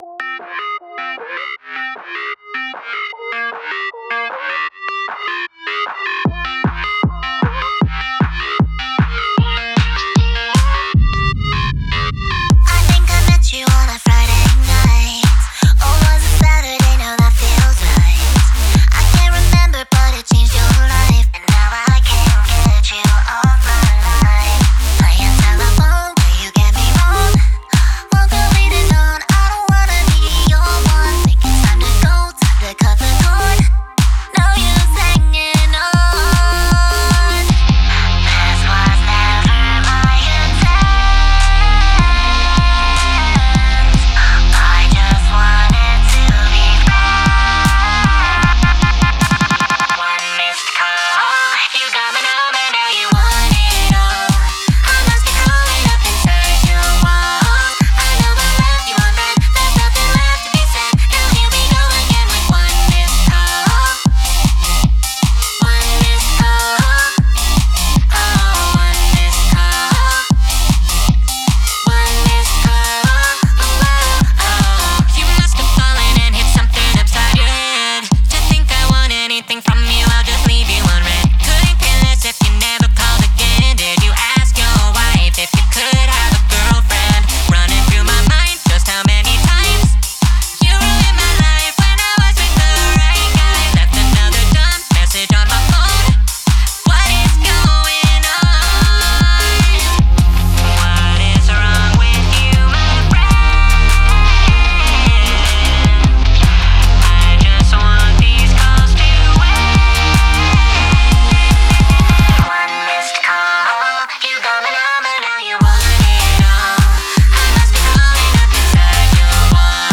un tema envolvente